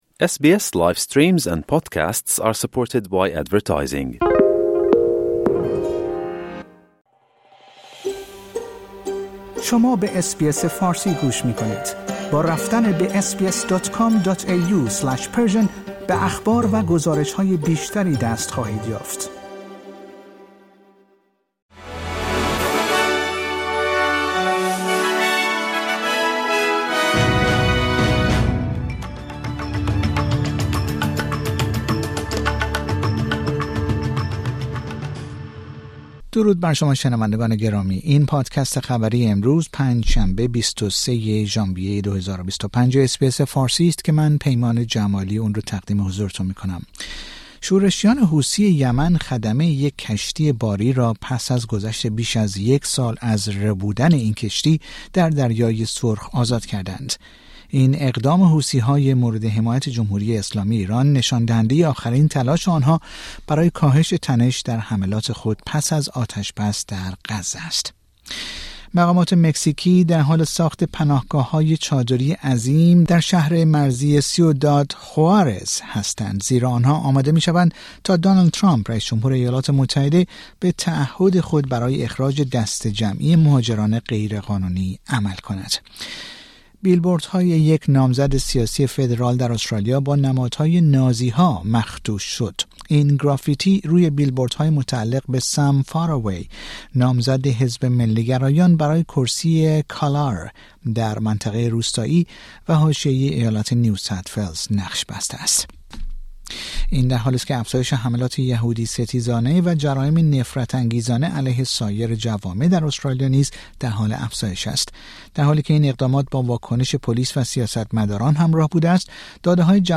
در این پادکست خبری مهمترین اخبار استرالیا در روز پنج شنبه ۲۳ ژانویه ۲۰۲۵ ارائه شده است.